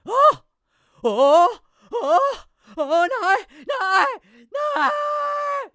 traf_scared2.wav